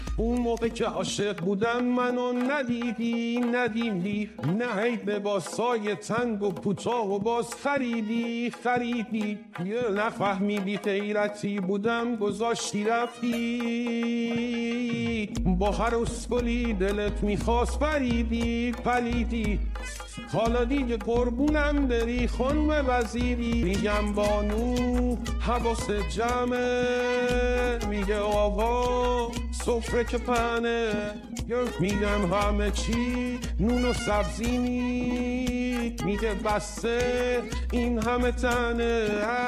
(((📍آهنگ سانسور شده)))) نسخه دمو و سانسور شدس👍